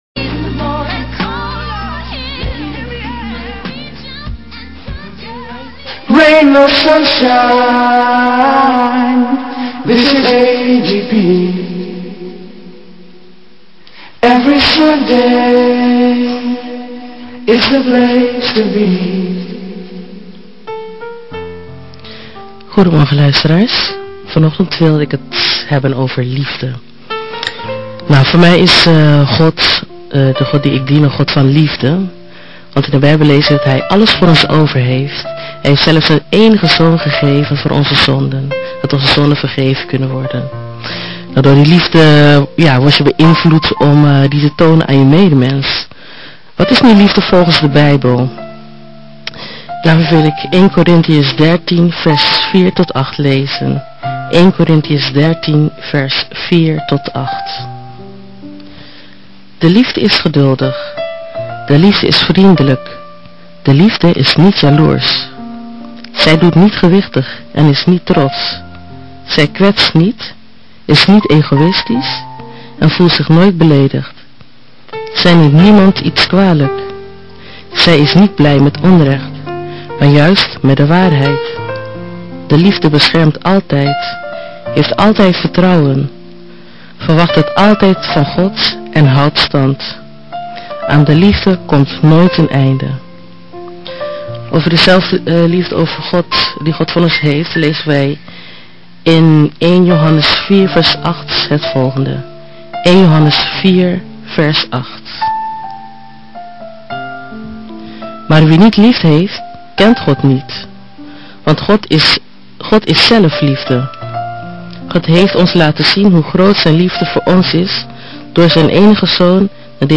Opnamelocatie: AGP FM Studio Rotterdam